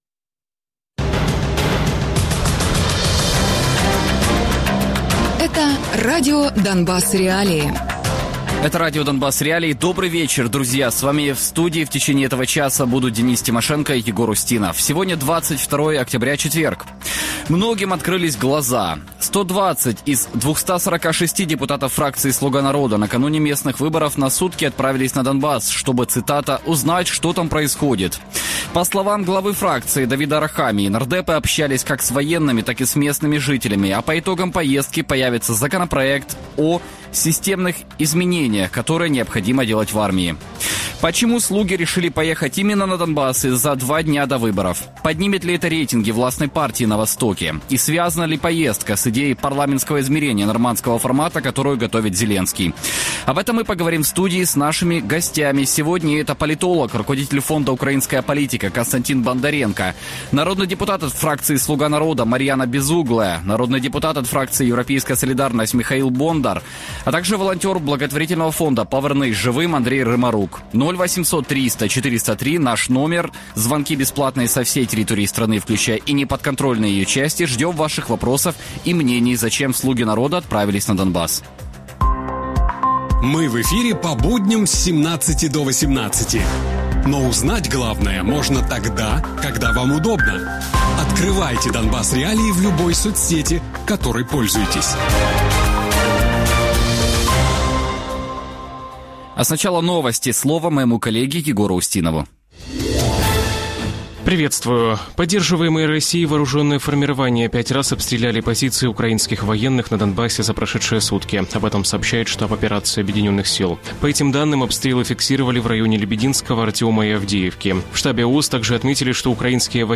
Мар'яна Безугла - народна депутатка, фракція «Слуга народу», Михайло Бондар - народний депутат, фракція «Європейська солідарність»